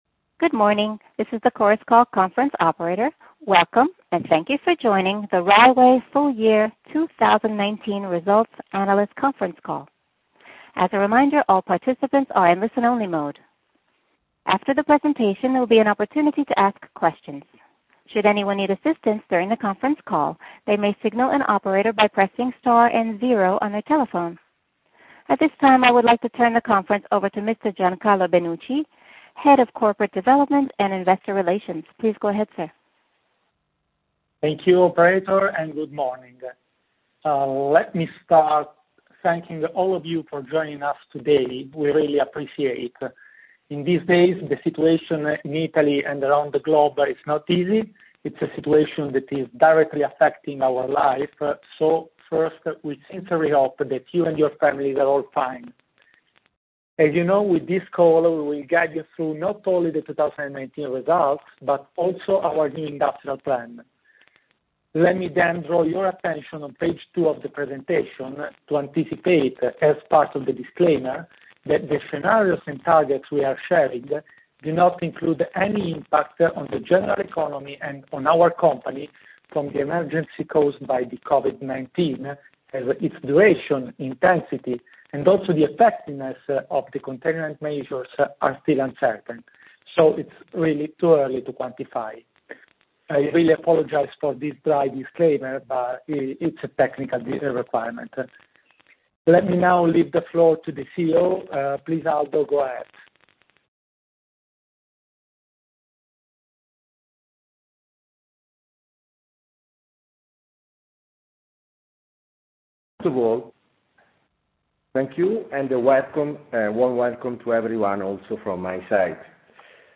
Rai Way Conference Call